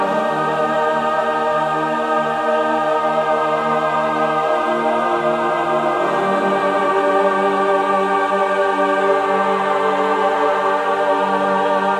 描述：G大调
标签： 80 bpm Cinematic Loops Choir Loops 2.02 MB wav Key : Unknown
声道立体声